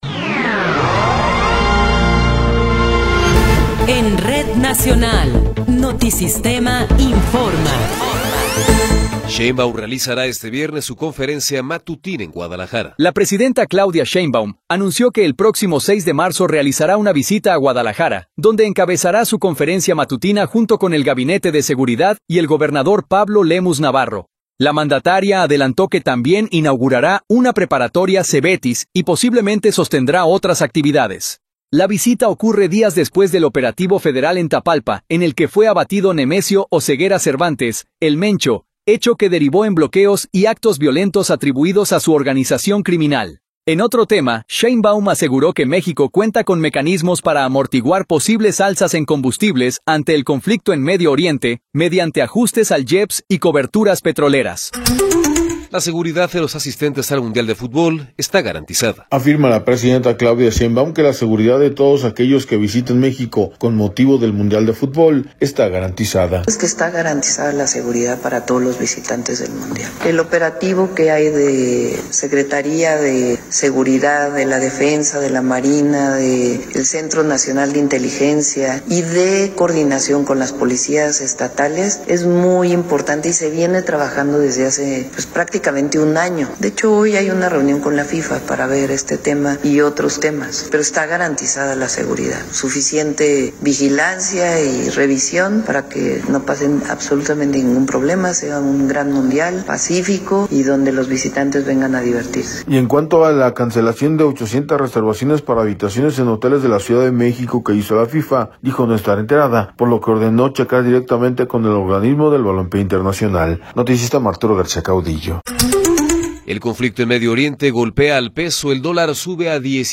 Noticiero 12 hrs. – 4 de Marzo de 2026